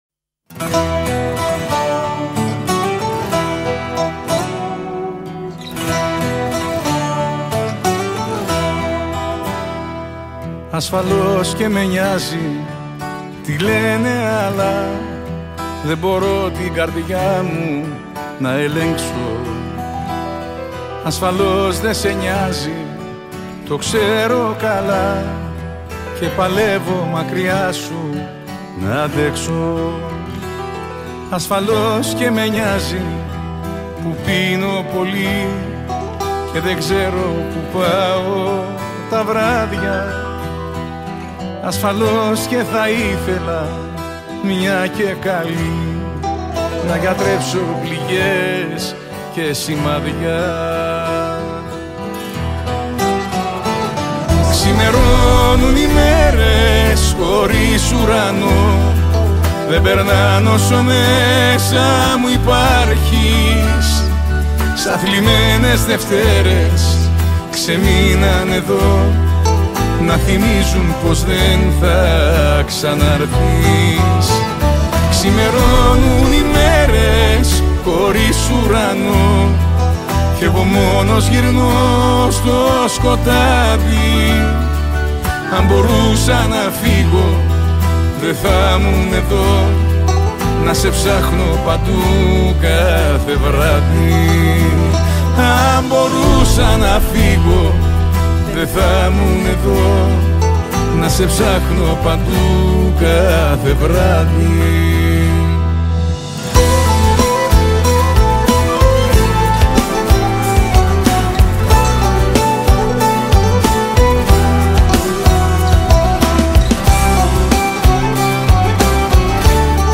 Жанр: ЖАНР / ΕΙΔΟΣ ΛΑΪΚΆ / ΣΥΓΧΡΟΝΗ
ΠΙΆΝΟ- ΛΑΟΎΤΟ
ΜΠΆΣΟ
NEY
ΑΚΟΥΣΤΙΚΈΣ ΚΙΘΆΡΕΣ